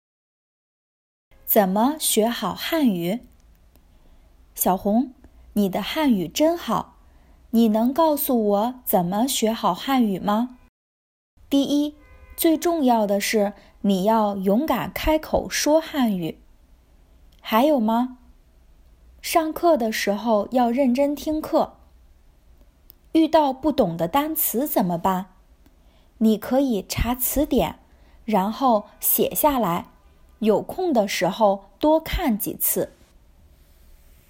This intermediate dialogue talks about how to improve your Mandarin!